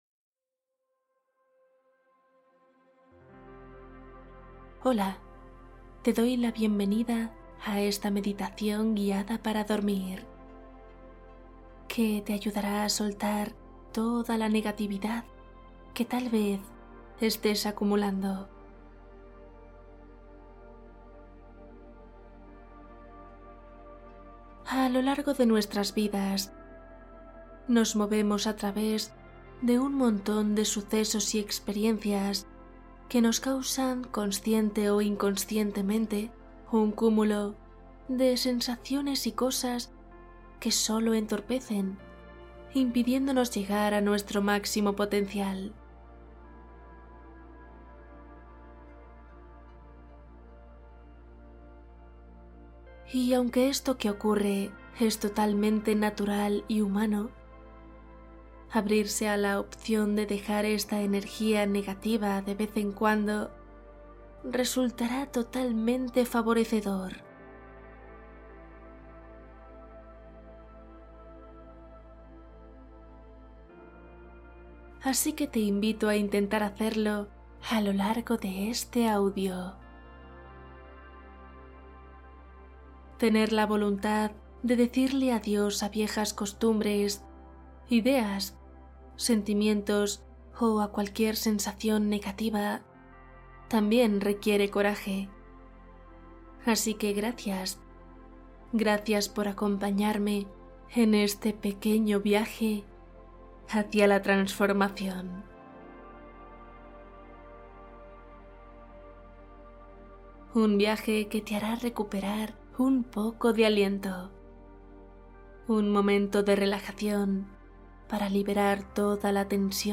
Meditación del sueño profundo | Suelta la negatividad antes de dormir